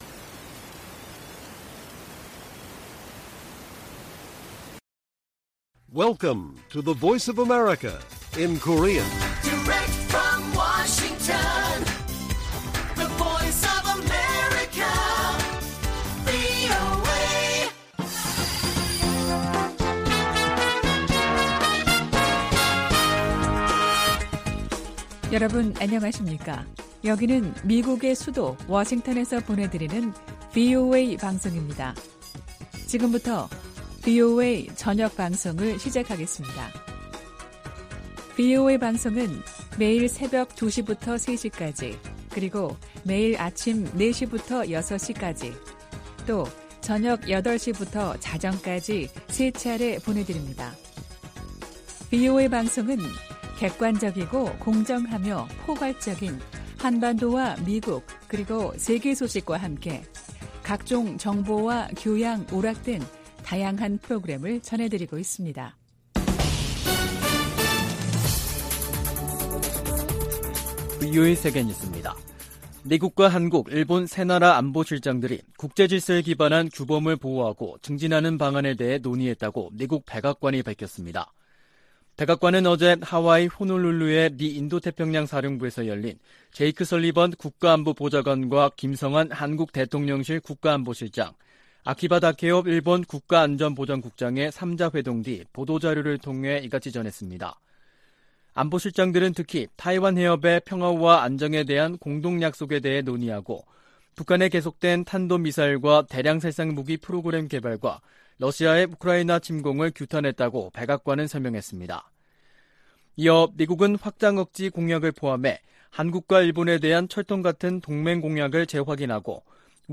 VOA 한국어 간판 뉴스 프로그램 '뉴스 투데이', 2022년 9월 2일 1부 방송입니다. 미국과 한국, 일본 안보실장들이 하와이에서 만나 북한 미사일 프로그램을 규탄하고 타이완해협 문제 등을 논의했습니다. 미국과 한국 간 경제 협력을 강화하기 위한 미 의원들의 움직임이 활발해지고 있습니다. 미국 검찰이 북한의 사이버 범죄 자금에 대한 공식 몰수 판결을 요청하는 문건을 제출했습니다.